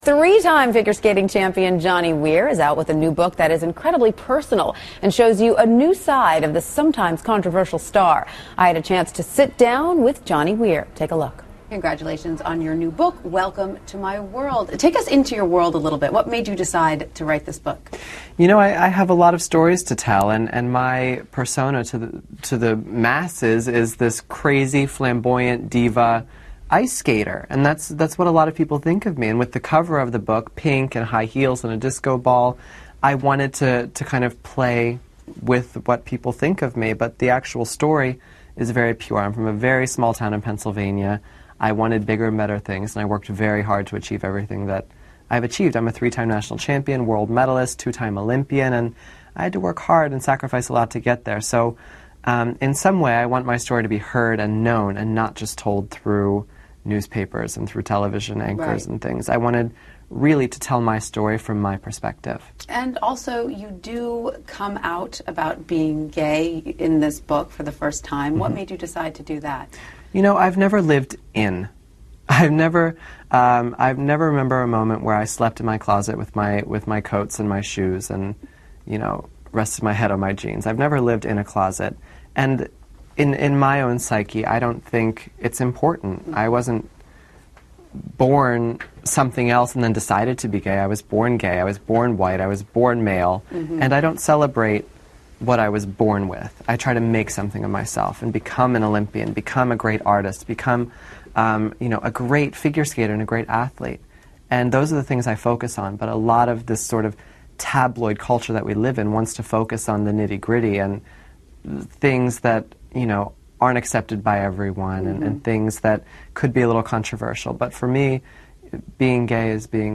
访谈录 2011-01-15&01-17 花样滑冰冠军约翰尼·威尔专 听力文件下载—在线英语听力室